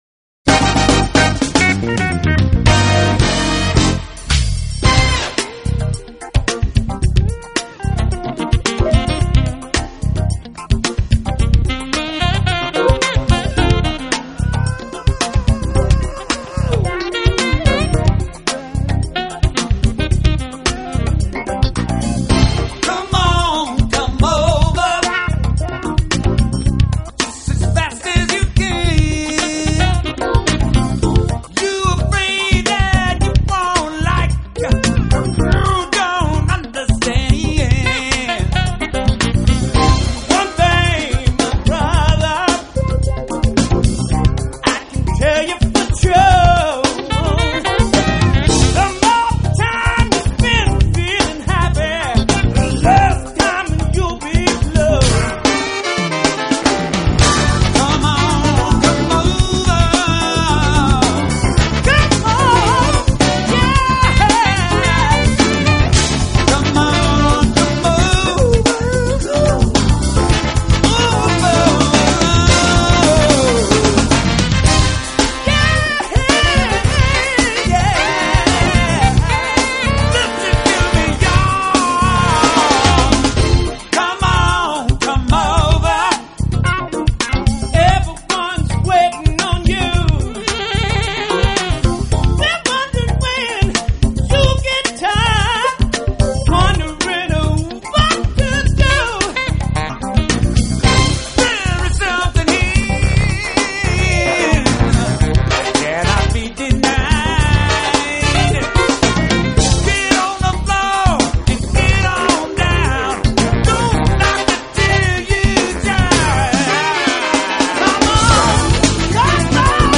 bass
Hammond Organ